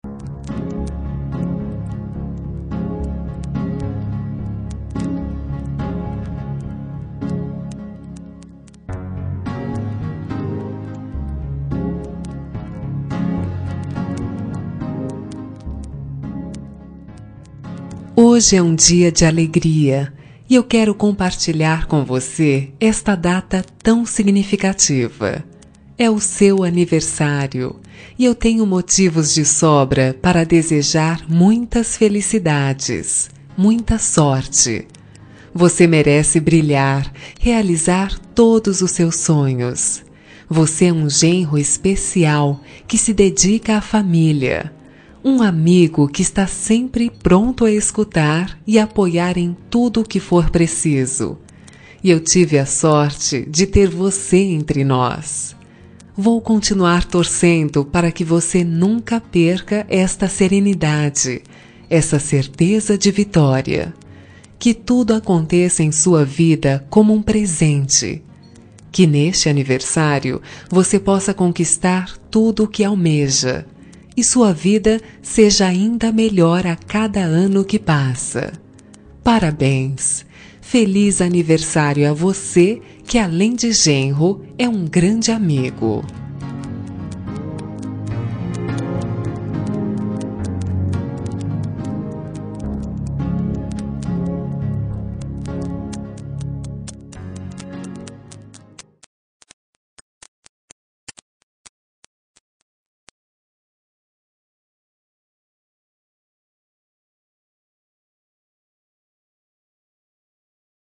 Aniversário de Genro – Voz Feminina – Cód: 2421